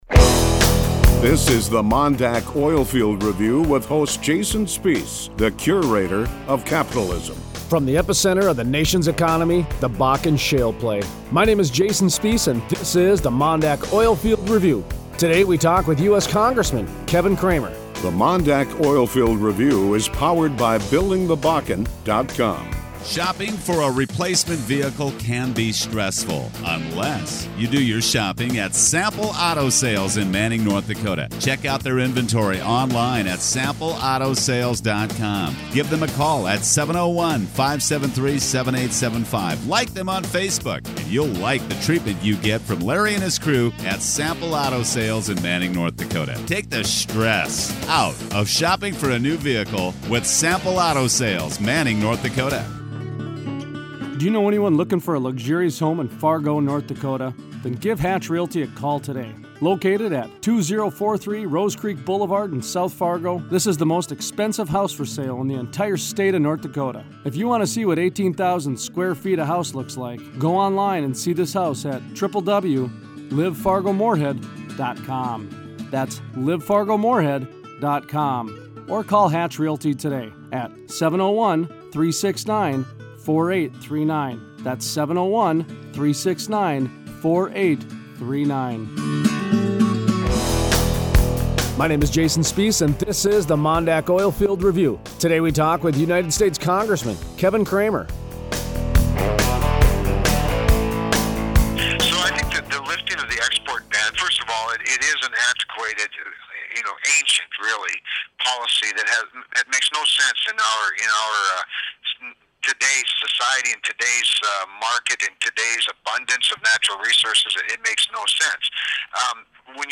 Monday 7/27 Interview: US Congressman Kevin Cramer Cramer talks about the Export Ban on Crude Oil and how the US has an abundance of supply.